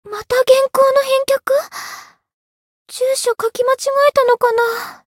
灵魂潮汐-爱莉莎-问候-晴天深夜-亲密.ogg